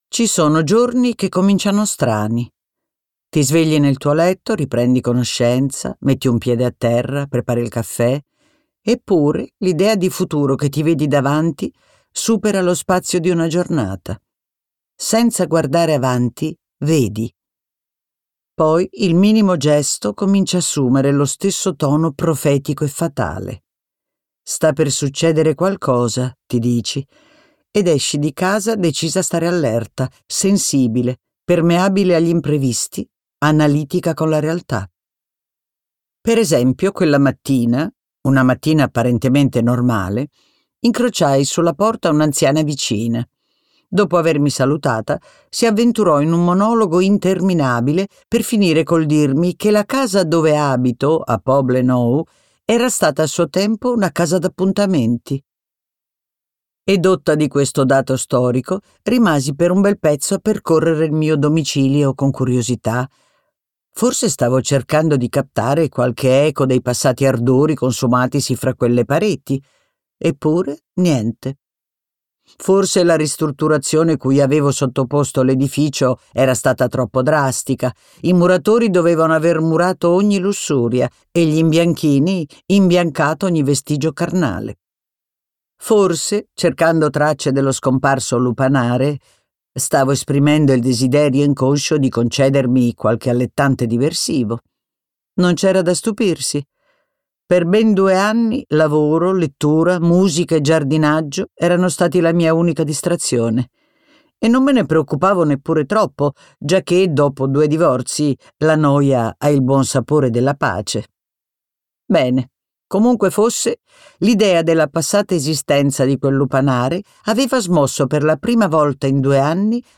letto da Anna Bonaiuto
Versione audiolibro integrale